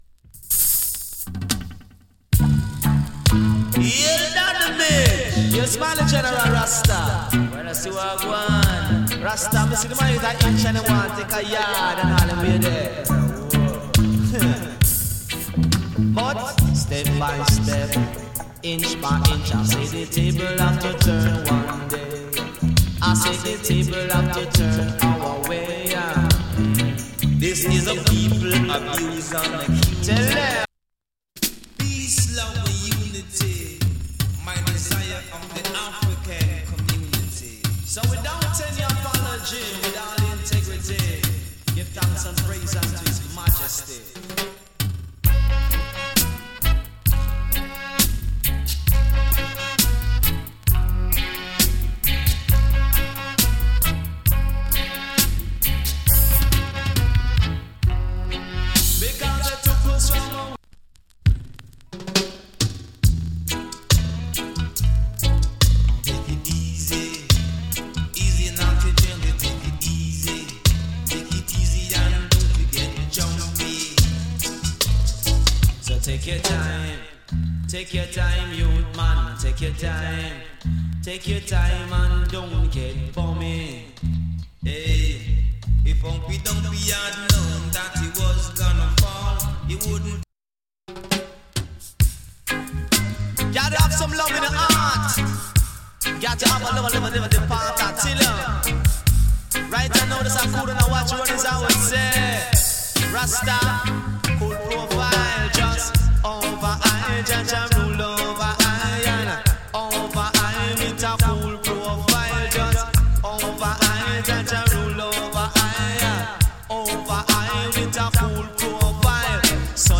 EARLY DANCE HALL DEE-JAY ALBUM